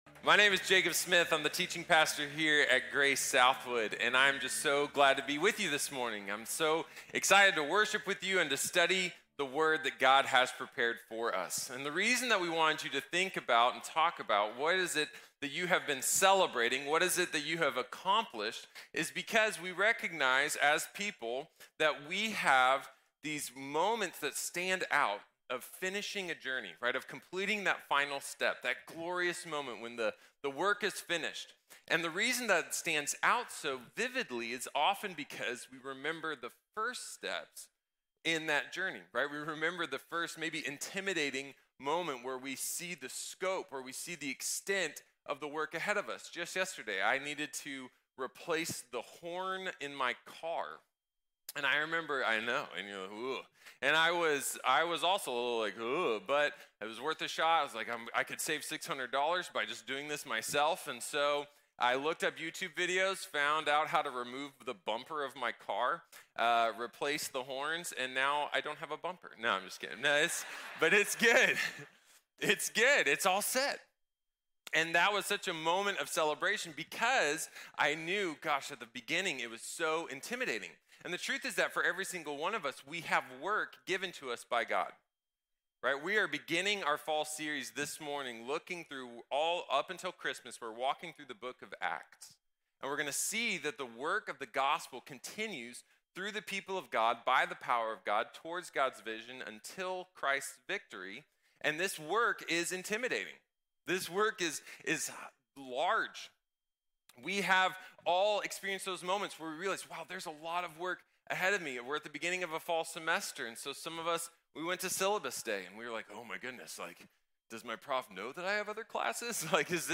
El trabajo del Evangelio | Sermón | Iglesia Bíblica de la Gracia